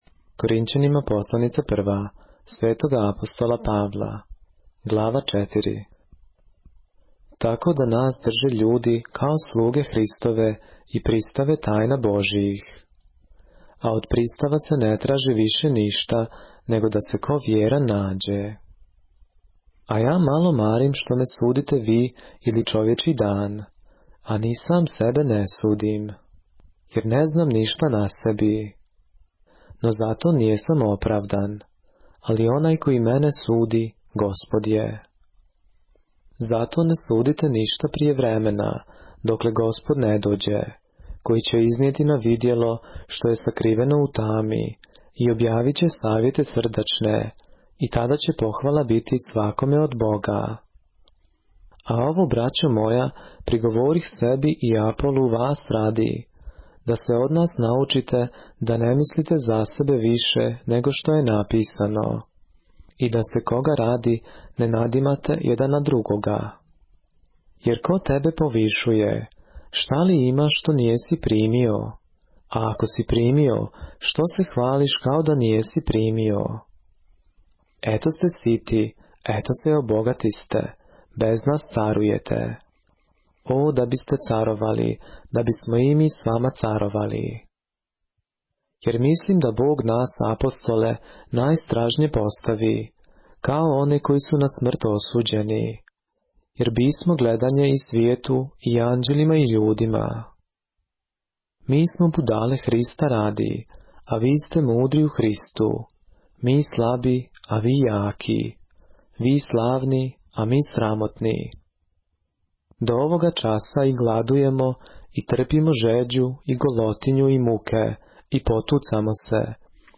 поглавље српске Библије - са аудио нарације - 1 Corinthians, chapter 4 of the Holy Bible in the Serbian language